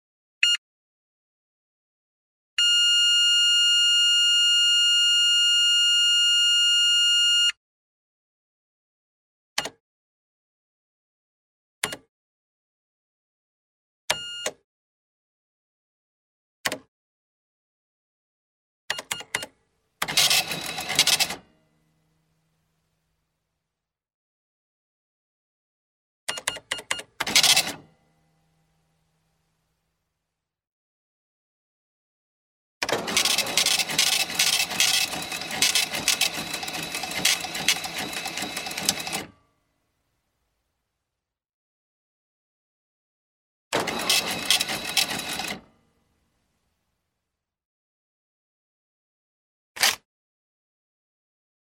Монеты звуки скачать, слушать онлайн ✔в хорошем качестве